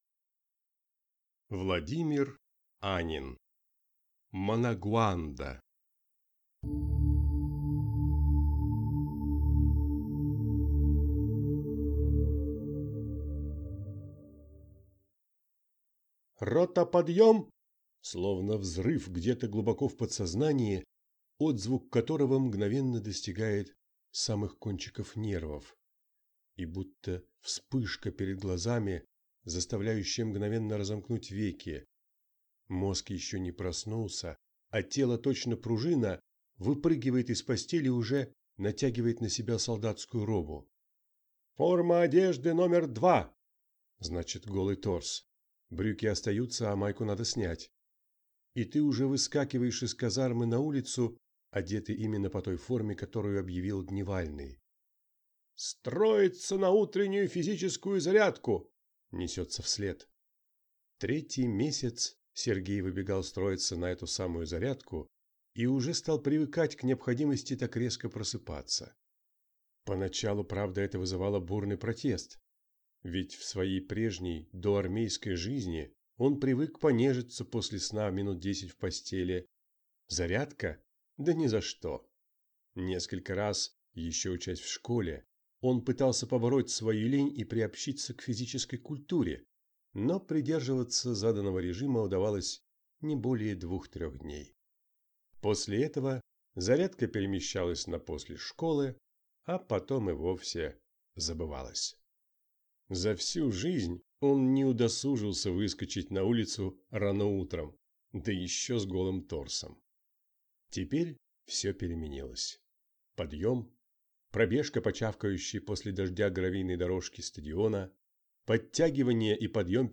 Аудиокнига Манагуанда | Библиотека аудиокниг
Прослушать и бесплатно скачать фрагмент аудиокниги